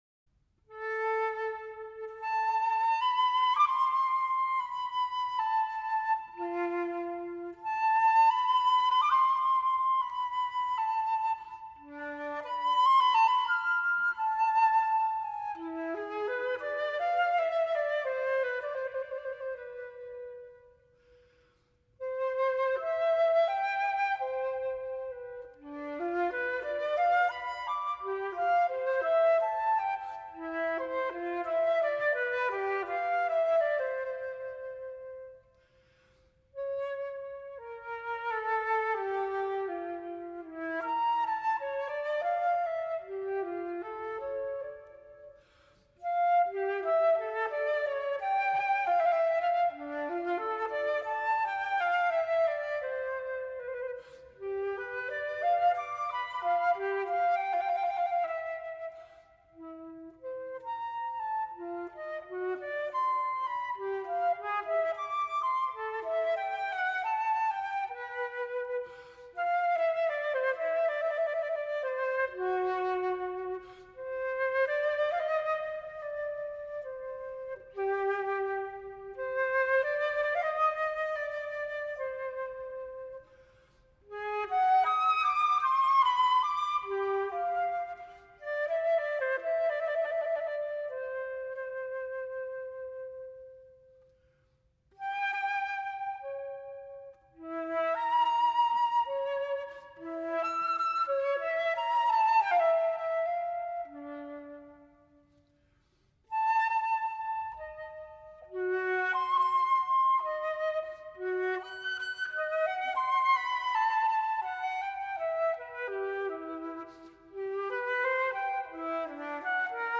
<<Из концертов для флейты с оркестром Карла Филиппа Эммануила Баха: H426 - Allegro dimolto, H438 - Largo consordini, mesto и
H562 - Poco Adajio (без оркестра). Флейтист Жан-Пьер Рампал, дирижер Янош Ролла. Запись в Итальянском институте Будапешта, 1986.